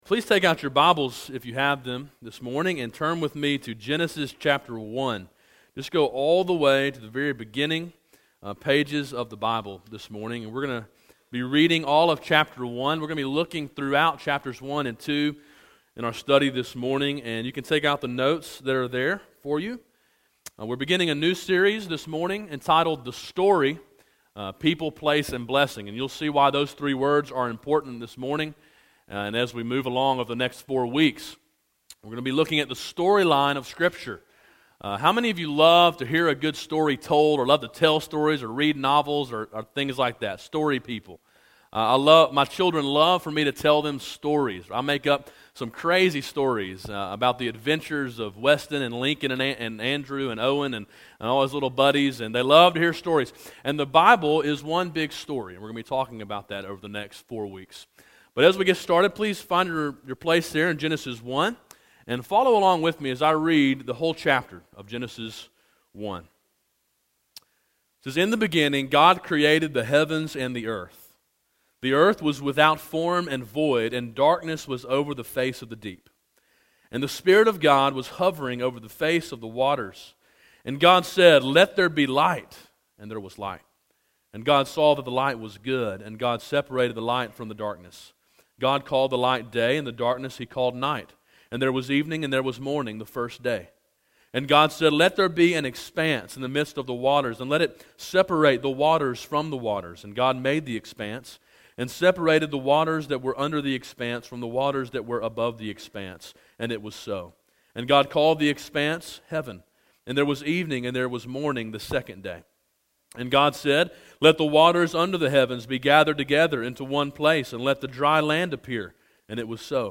Sermon Audio: “Creation” – Calvary Baptist Church